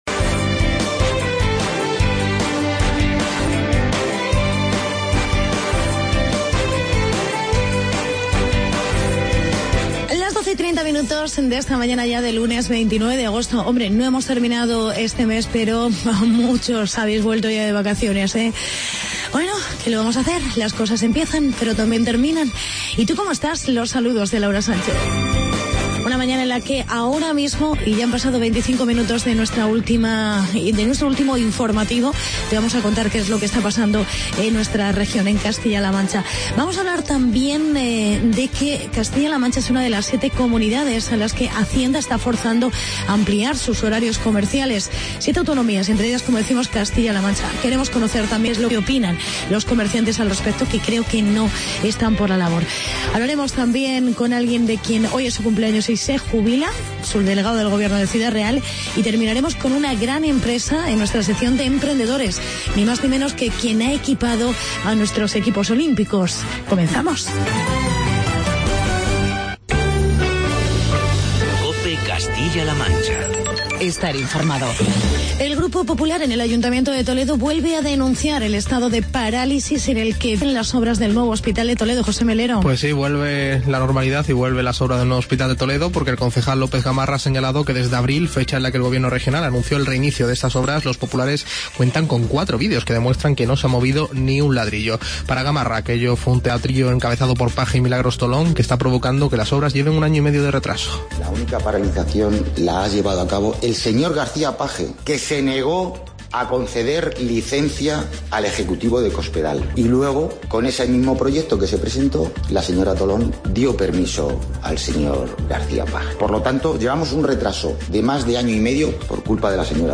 Hablamos con el subdelegado...